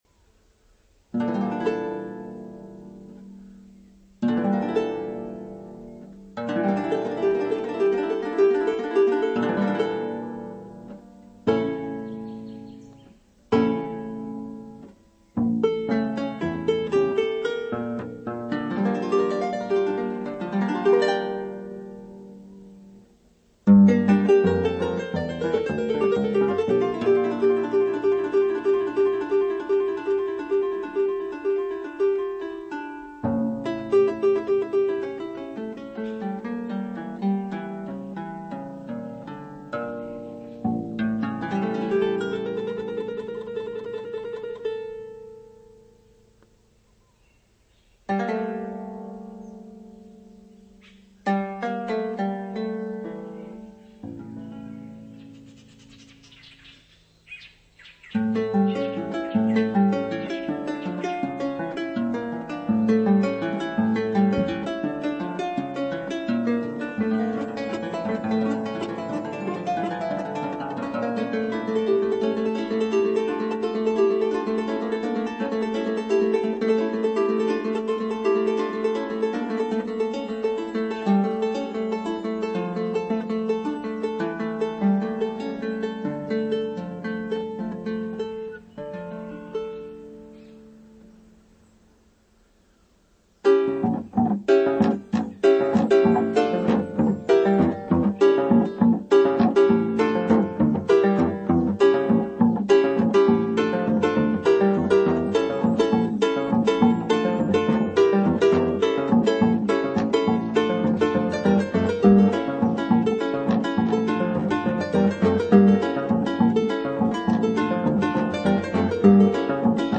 Llanero harpist
recorded live on a farm near Barinas, Venezuela.